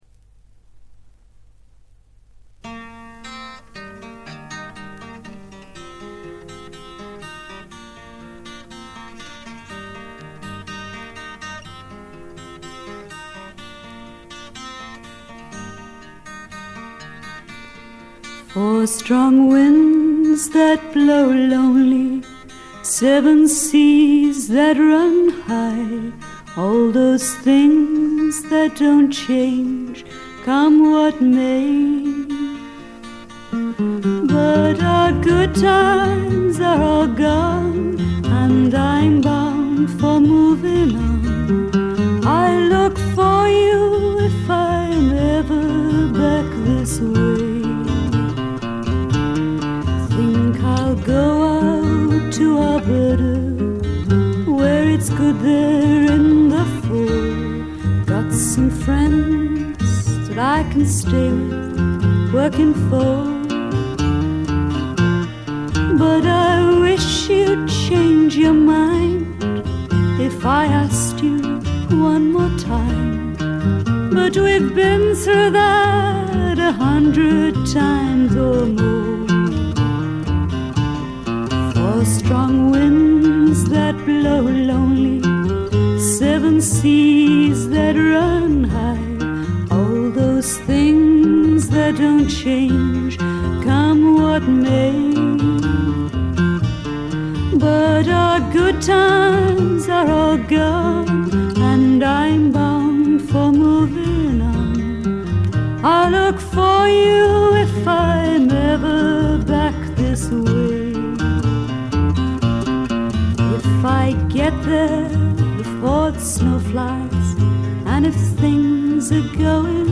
全体に軽微なバックグラウンドノイズ。
英国フィメール・フォークの大名作でもあります。
内容はというとほとんどがトラディショナル・ソングで、シンプルなアレンジに乗せた初々しい少女の息遣いがたまらない逸品です。
モノラル盤です。
試聴曲は現品からの取り込み音源です。